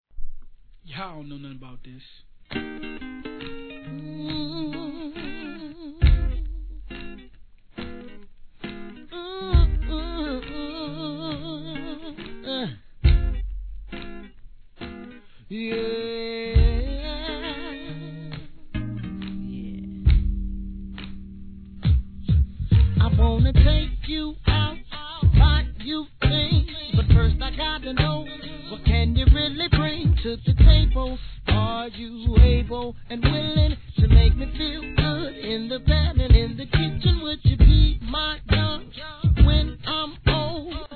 HIP HOP/R&B
2003年美声で歌い上げるミッドR&B♪